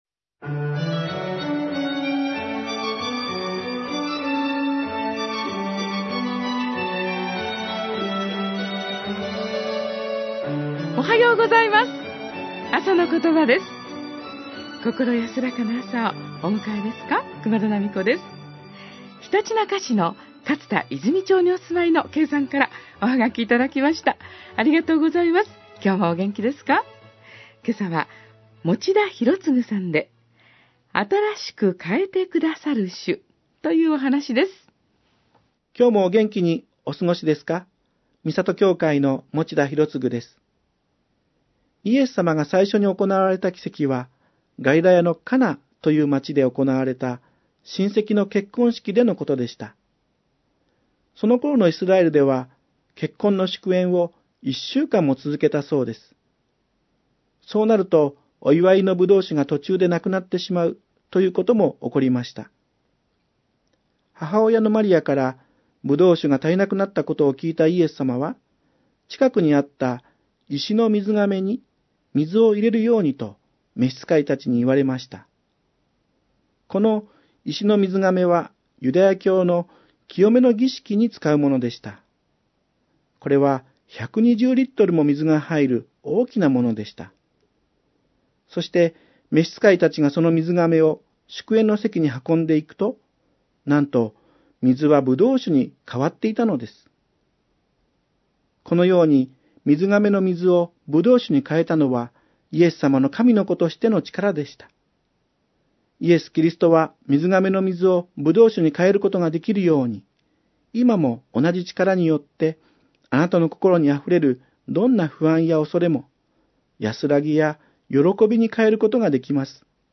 あさのことば　２０１４年５月１５日（木）放送 　　 あさのことば宛のメールはこちらのフォームから送信ください